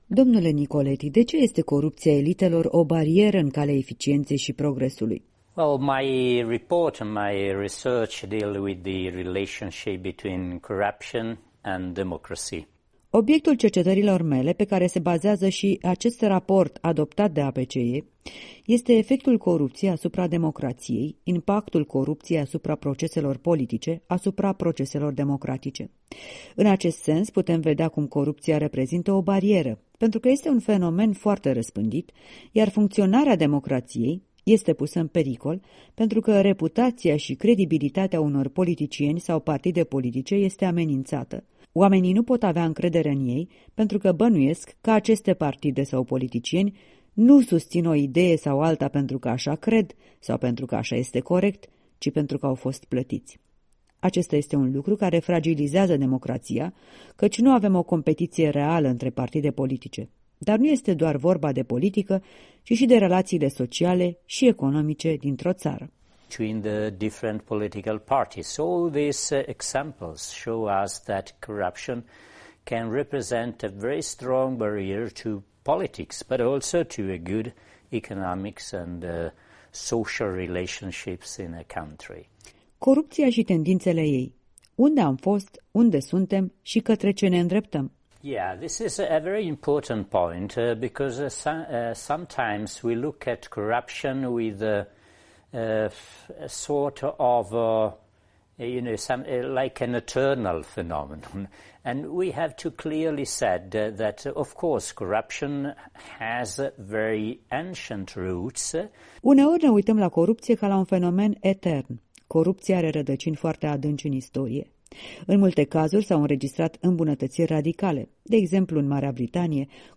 Michele Nicoletti, președintele Biroului Executiv al PACE răspunde întrebărilor